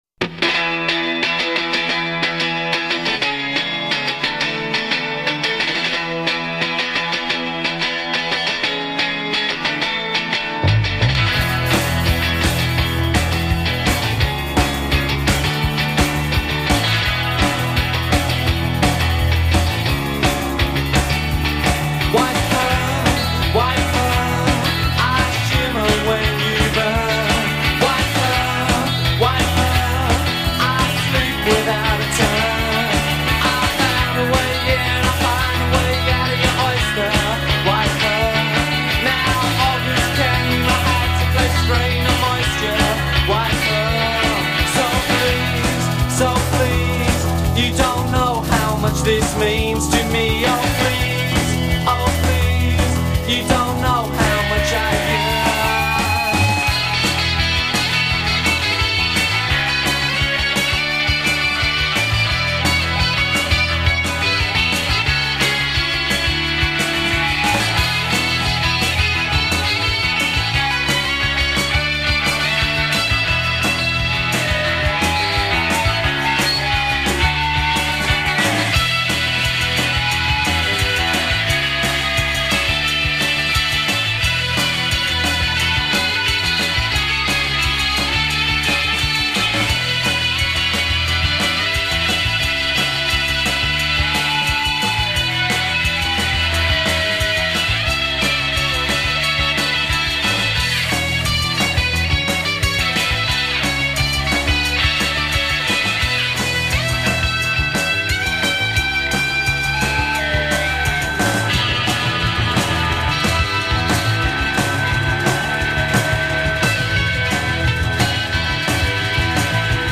guitars
formerly of Creation hopefuls Blow uUp on drums.